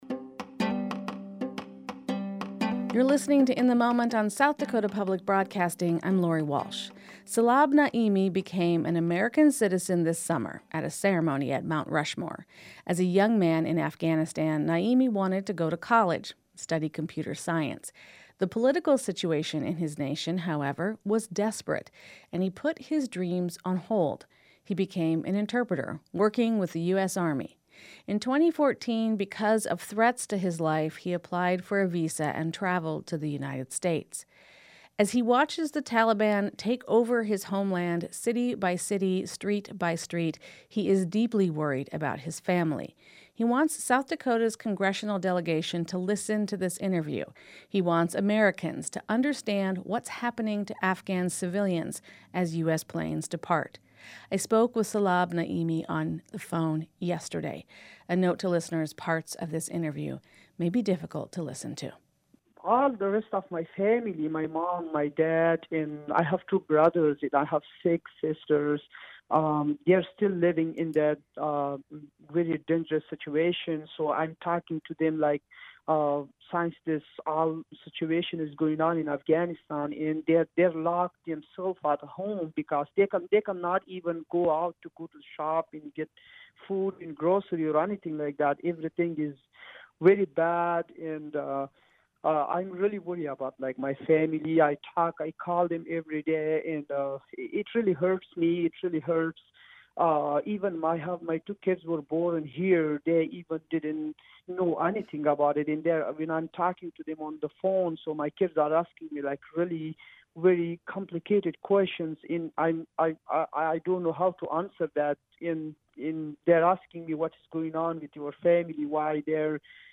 This story comes from a recent interview on SDPB's weekday radio program, "In the Moment."